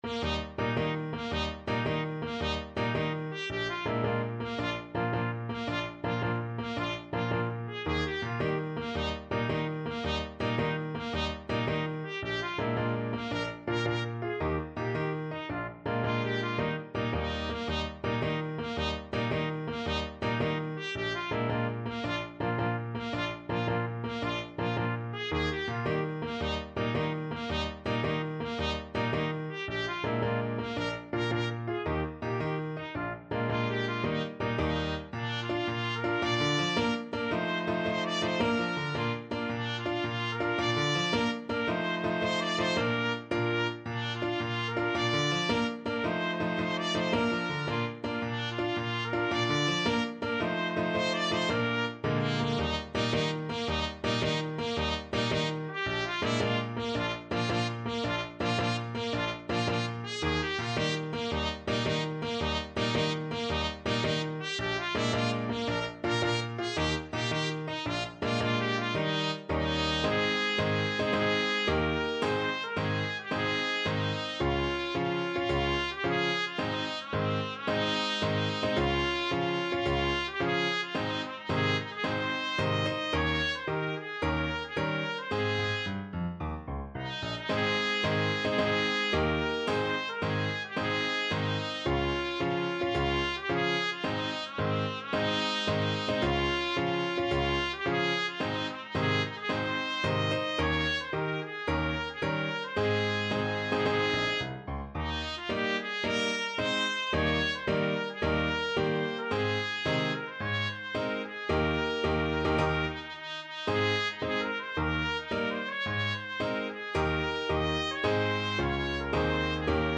Trumpet
Eb major (Sounding Pitch) F major (Trumpet in Bb) (View more Eb major Music for Trumpet )
6/8 (View more 6/8 Music)
Bb4-Eb6
Classical (View more Classical Trumpet Music)